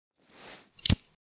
lighter_draw.ogg